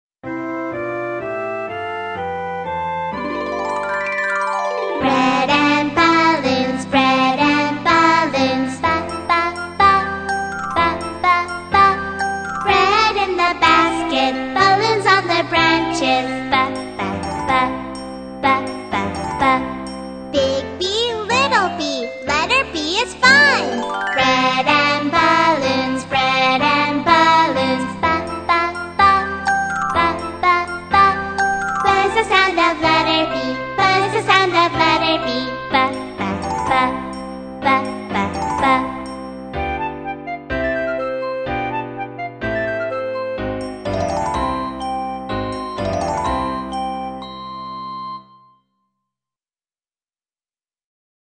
在线英语听力室英语儿歌274首 第117期:Letter b的听力文件下载,收录了274首发音地道纯正，音乐节奏活泼动人的英文儿歌，从小培养对英语的爱好，为以后萌娃学习更多的英语知识，打下坚实的基础。